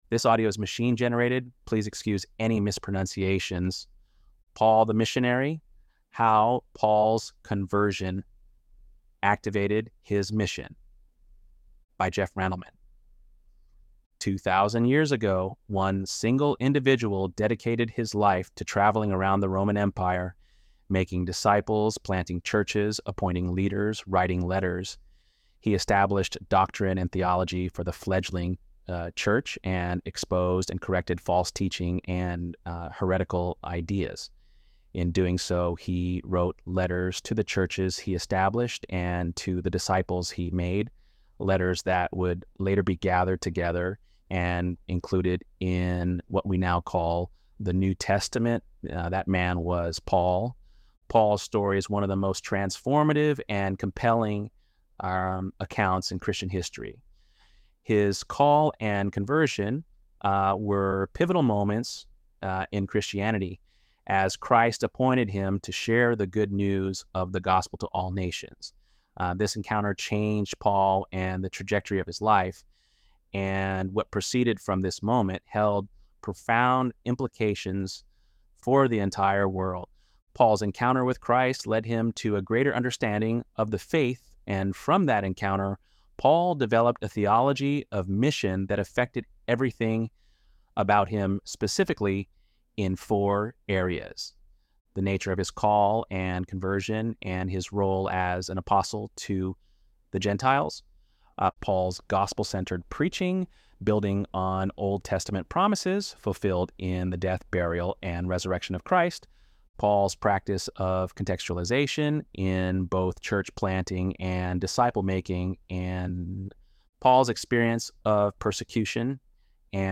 ElevenLabs_7.3_P.mp3